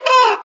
Clicking on the dragon will make a funny “squawk” sound!
squawk.mp3